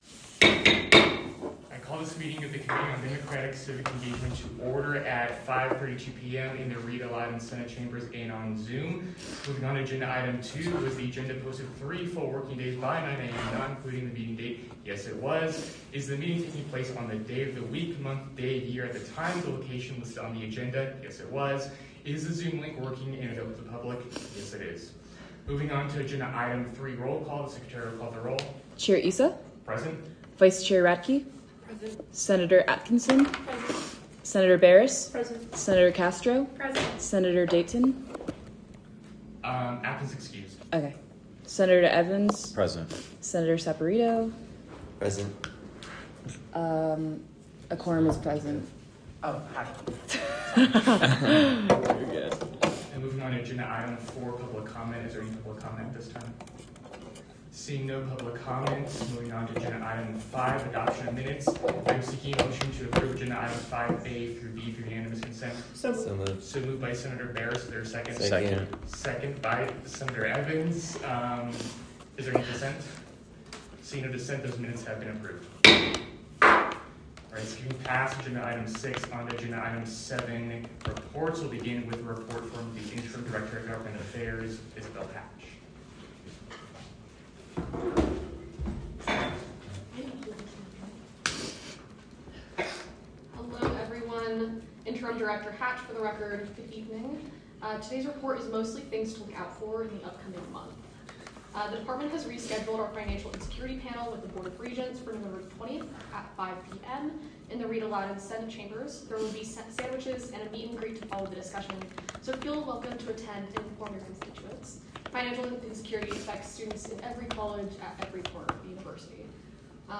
Location : Rita Laden Senate Chambers
Audio Minutes